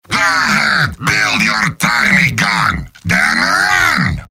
Robot-filtered lines from MvM. This is an audio clip from the game Team Fortress 2 .
{{AudioTF2}} Category:Heavy Robot audio responses You cannot overwrite this file.